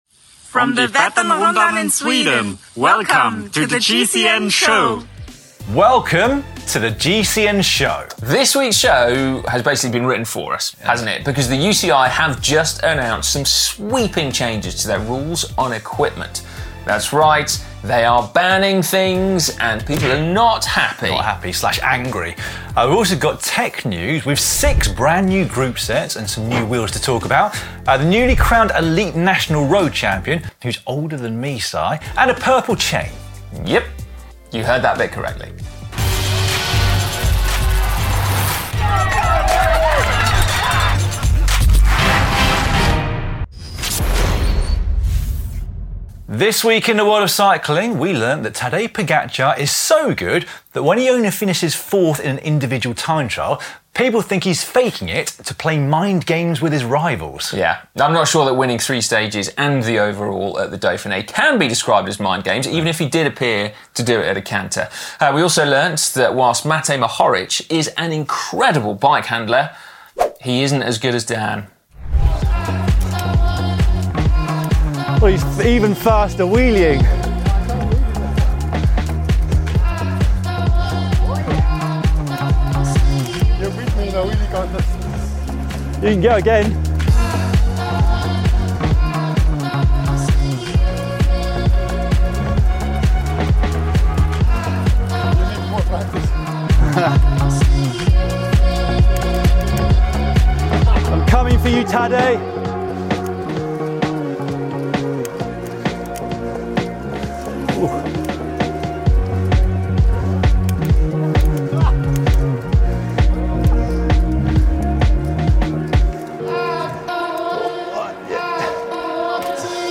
Presented by ex-pro riders, GCN offers a uniquely qualified insight into the world of c